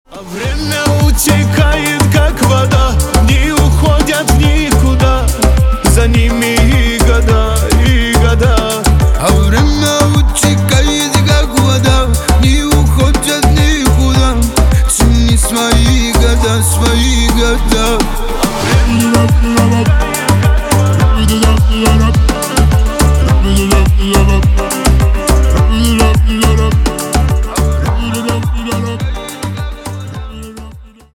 на русском восточные